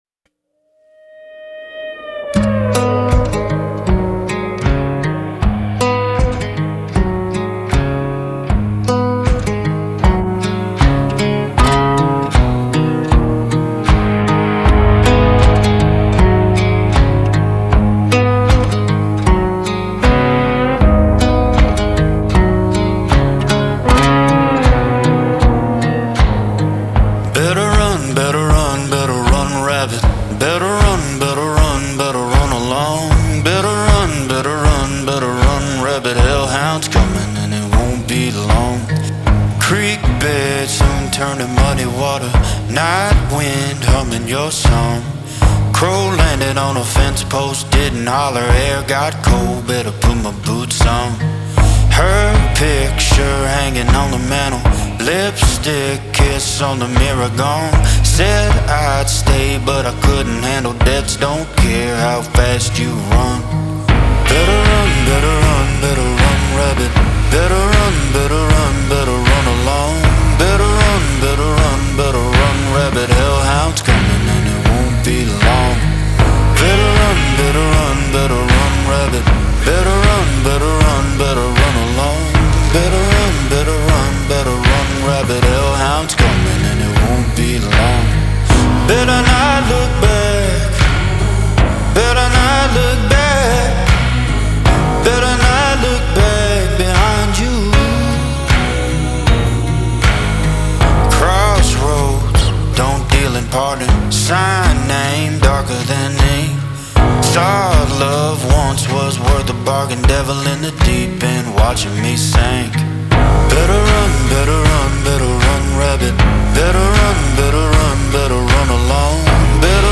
Pop
G# minor